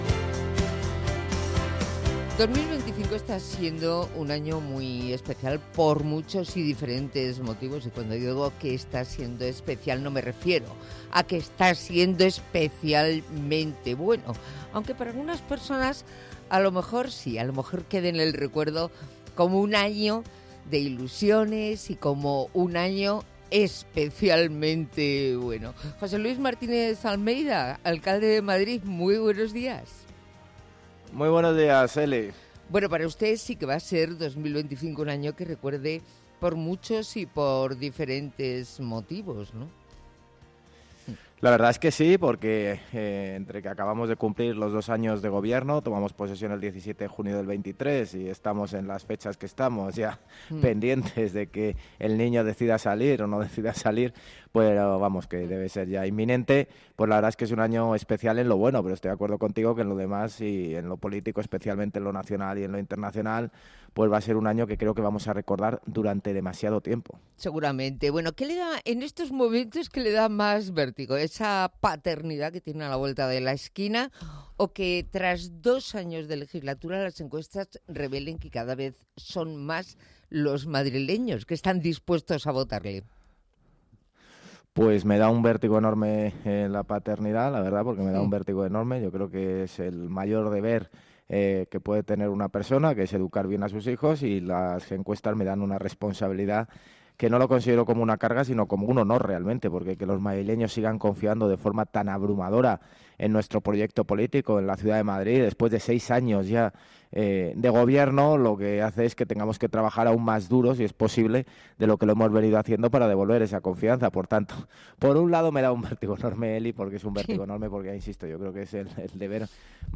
El alcalde de Madrid, José Luis Martínez-Almeida, marca, en una entrevista en Onda Madrid, la vivienda como la principal prioridad en la recta final de su legislatura y asegura que Madrid será la ciudad europea con mayor capacidad para la construcción de viviendas accesibles gracias a los nuevos desarrollos urbanísticos del Sureste, Madrid Nuevo Norte y Campamento.